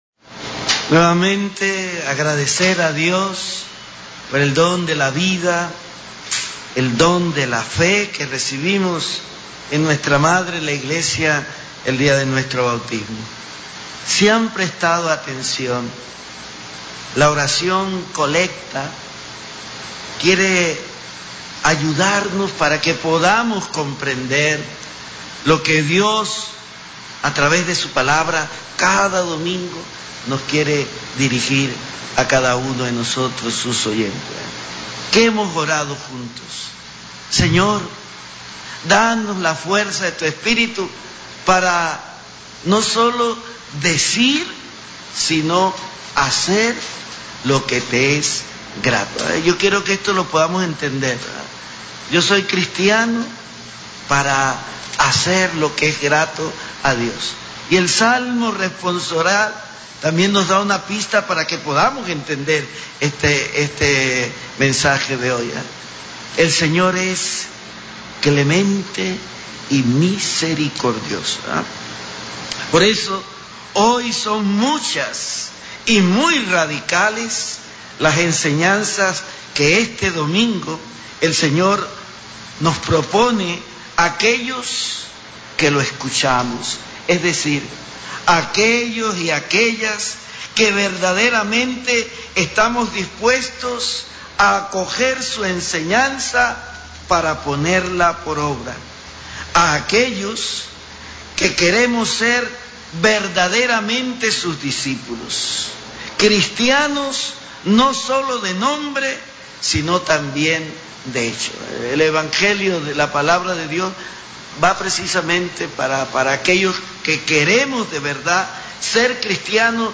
Homilía Domingo VII Ciclo C Mons. Jose Domingo Ulloa Mendieta OSA Capilla de la Universidad Católica Santa María de la Antigua 23 de febrero 2024